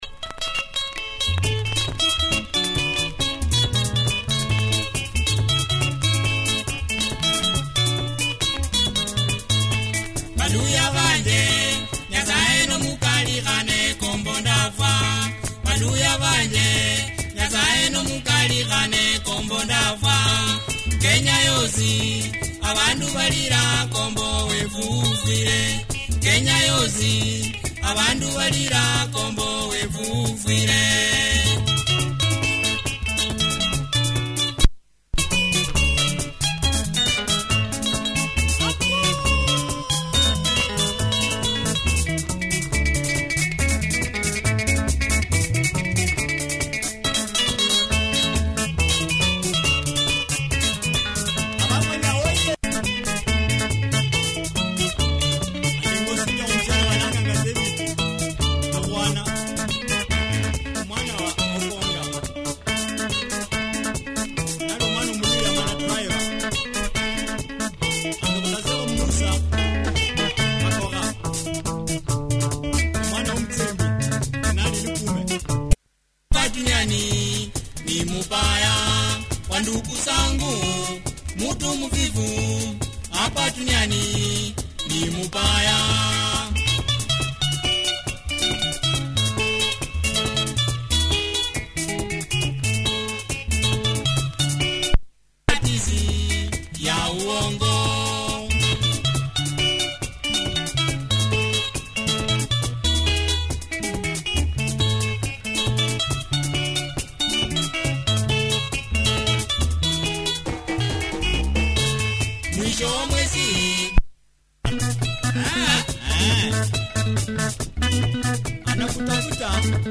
Tight Luhya benga, check audio of both sides! https